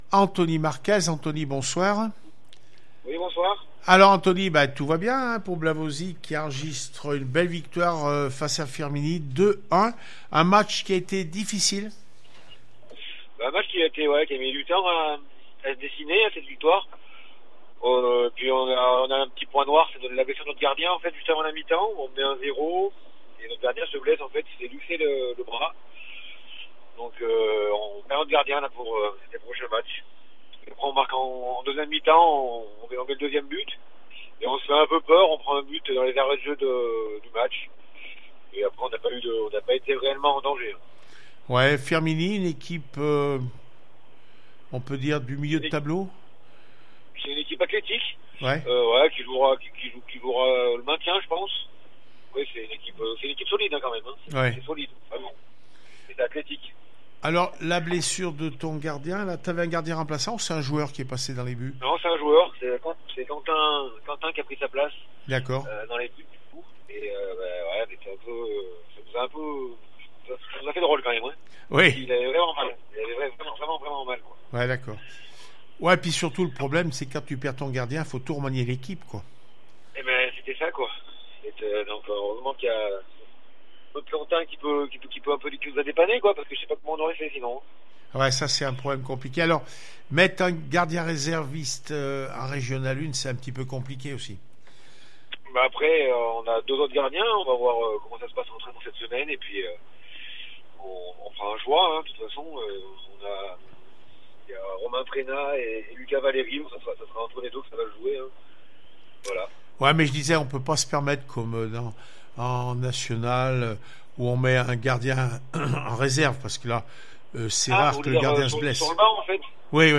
13 octobre 2020   1 - Sport, 1 - Vos interviews, 2 - Infos en Bref   No comments